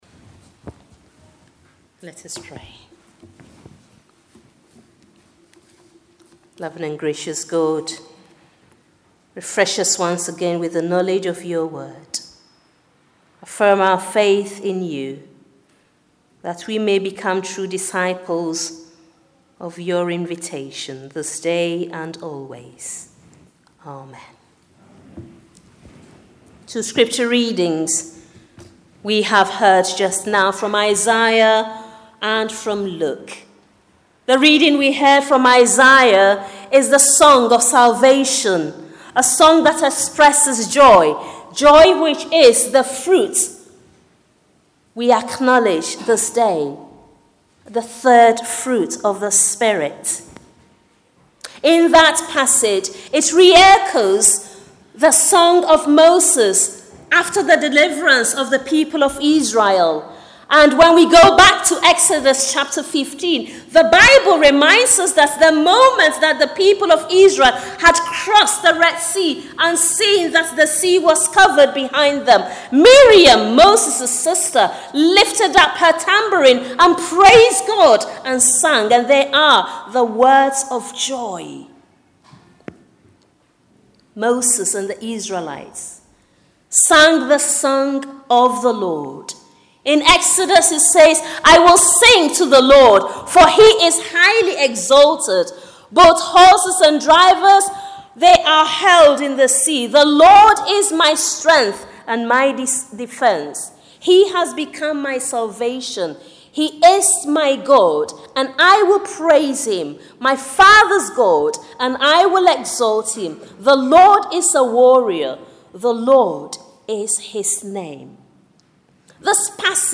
Sunday-13th-Sermon.mp3